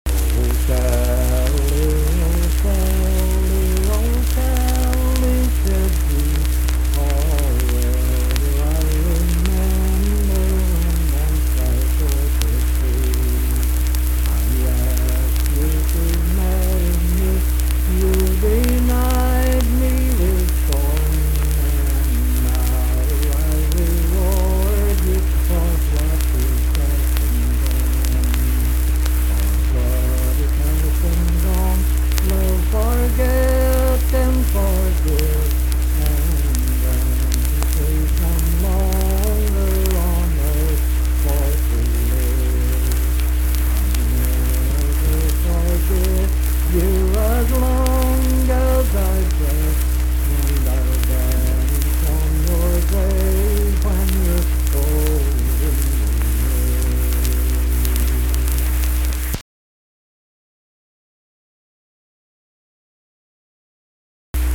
Unaccompanied vocal music
Voice (sung)
Webster County (W. Va.)